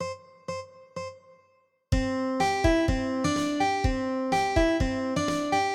のイントロ・リフ